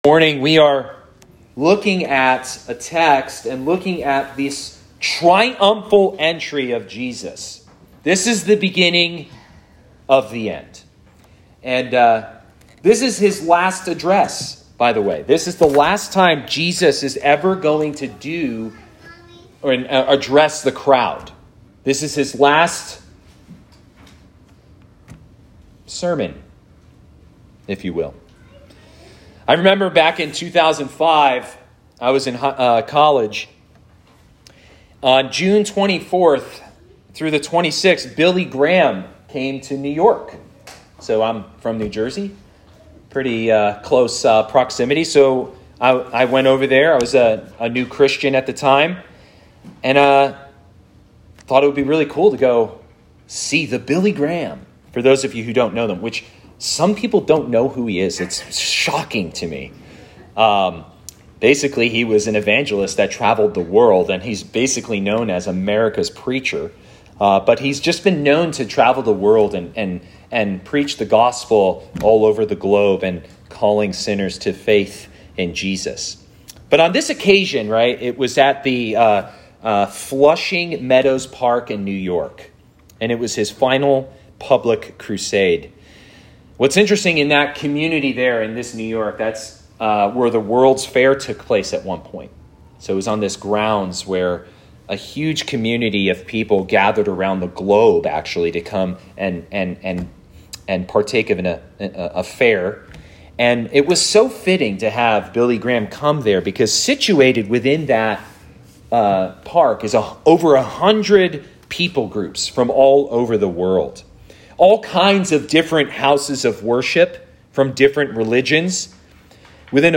All Sermons “The Humble King”